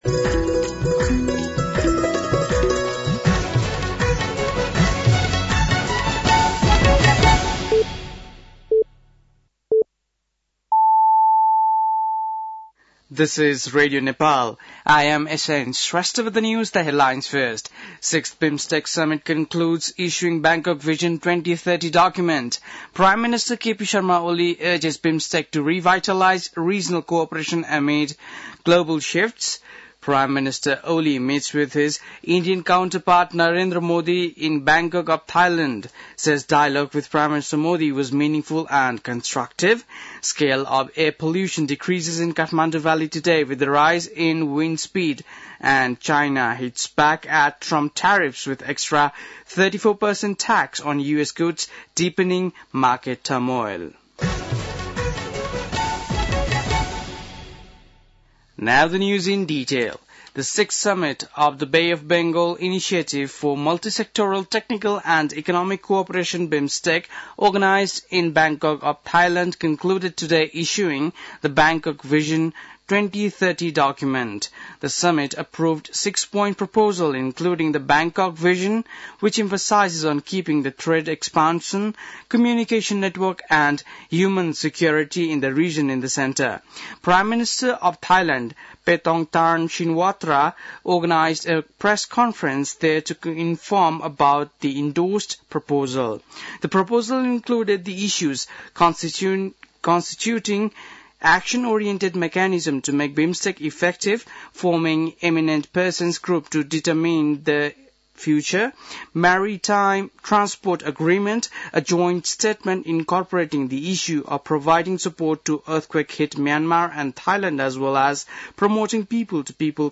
बेलुकी ८ बजेको अङ्ग्रेजी समाचार : २२ चैत , २०८१